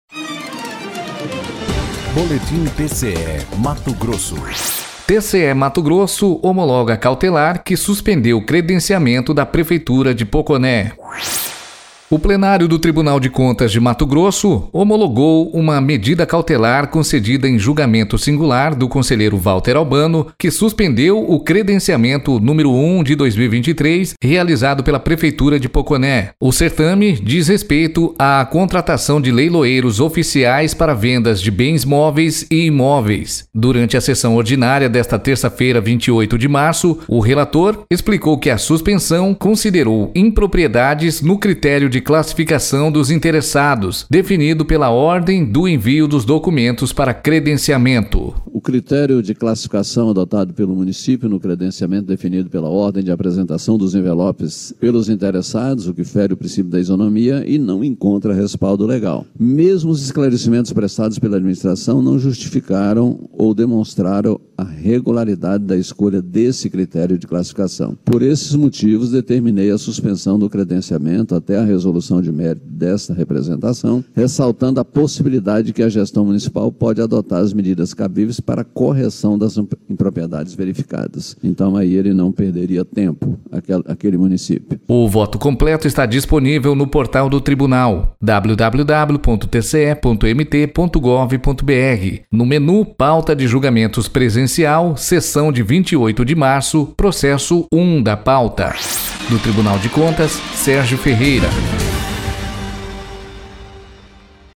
Sonora : Valter Albano – conselheiro do TCE-MT